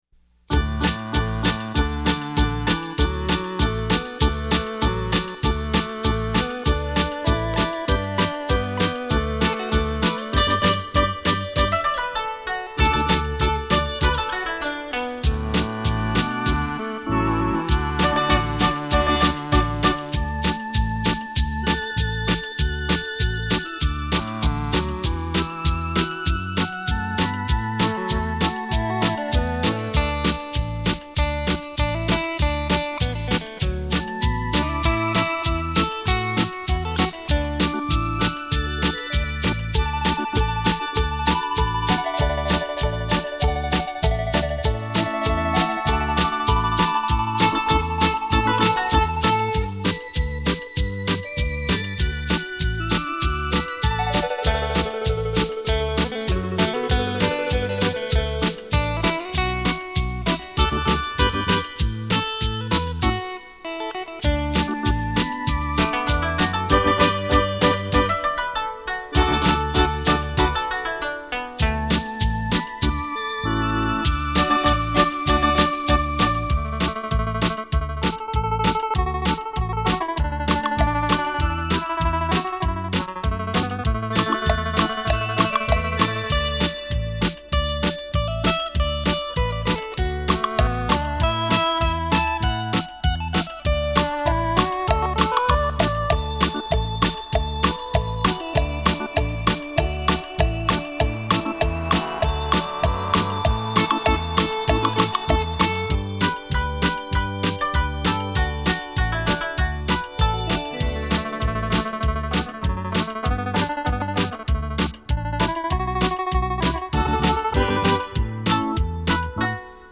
专辑语种：演奏